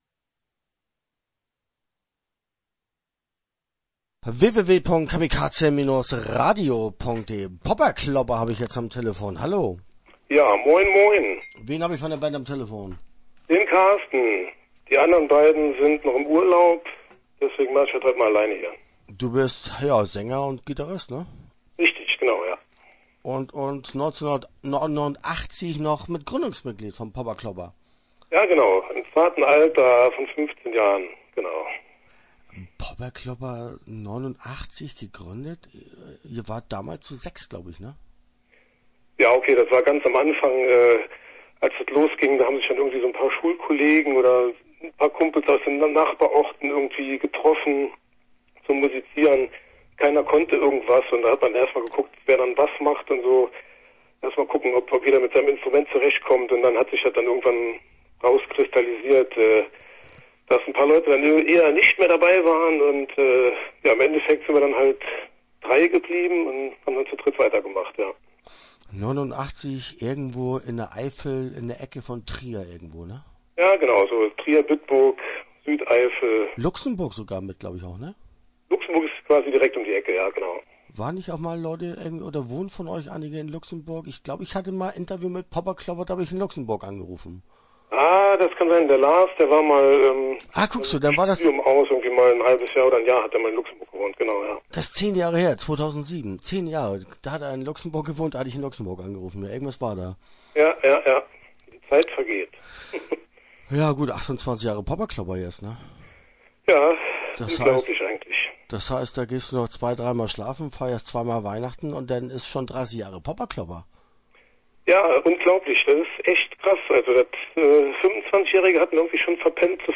Start » Interviews » Popperklopper